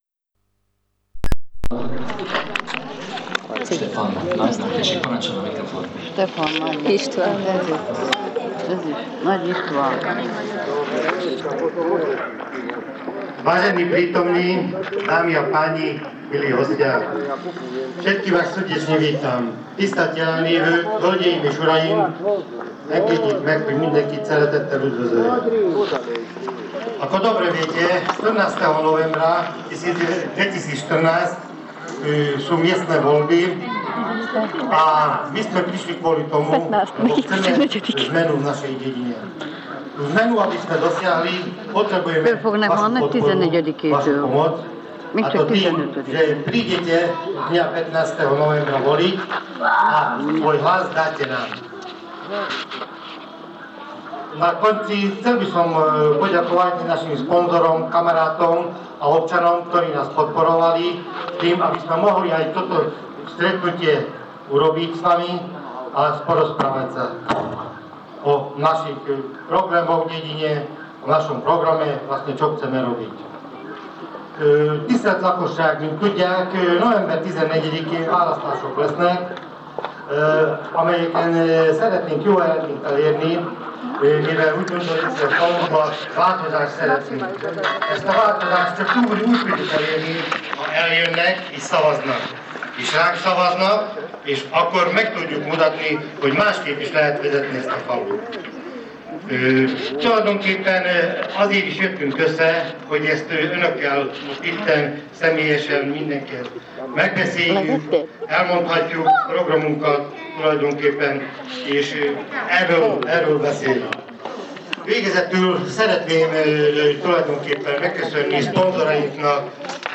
Predvolebný prejav